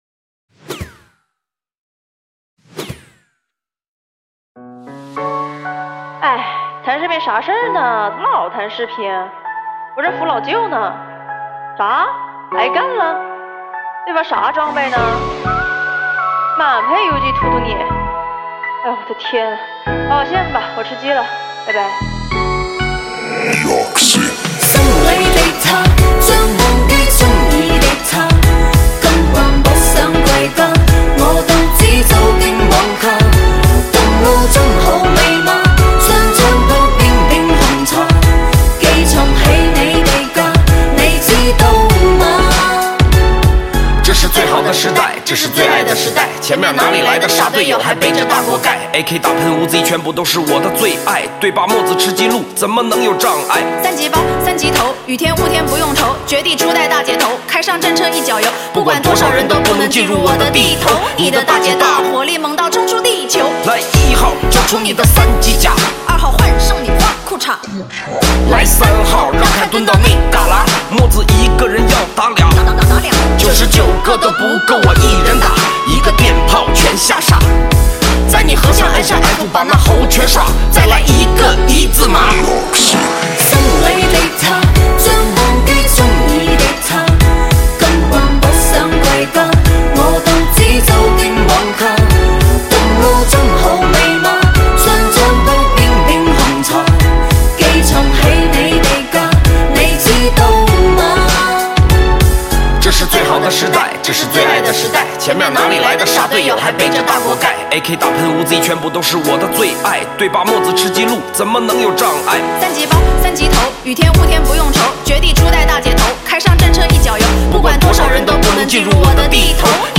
推荐2019年最新、好听的网络流行歌曲。
车载音乐,十倍音效。